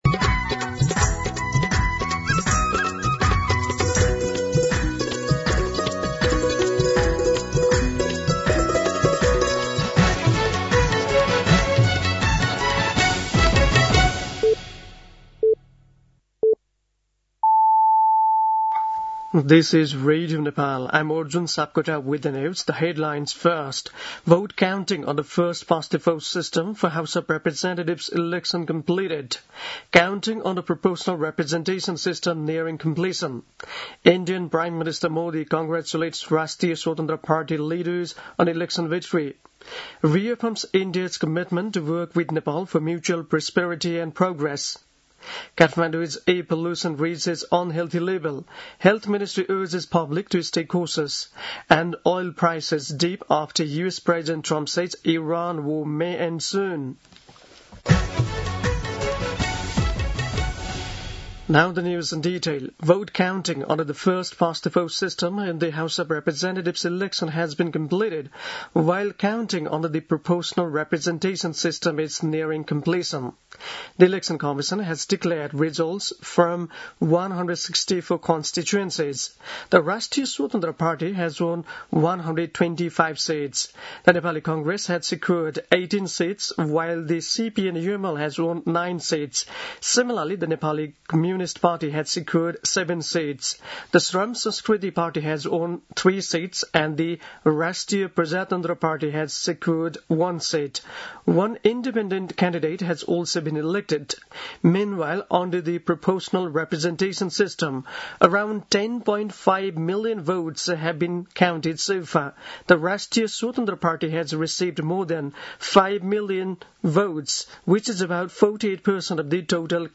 दिउँसो २ बजेको अङ्ग्रेजी समाचार : २६ फागुन , २०८२
2pm-English-News-11-26.mp3